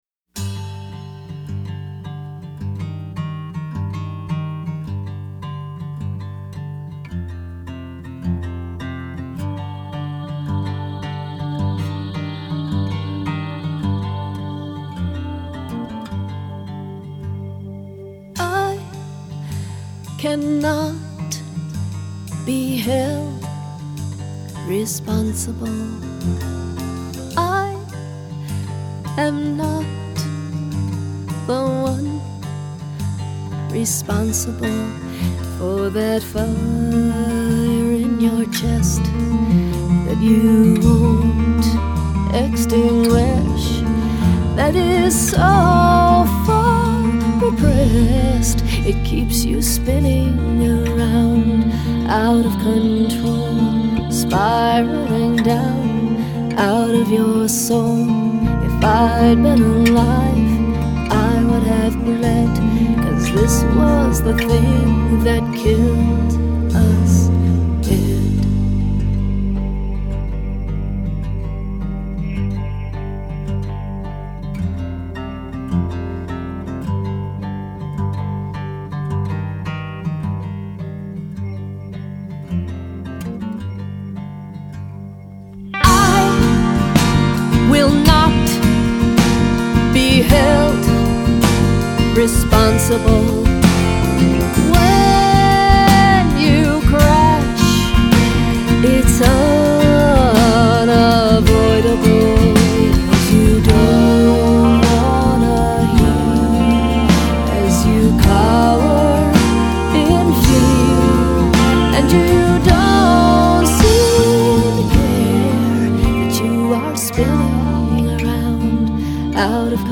Ballad Fmvx, Full band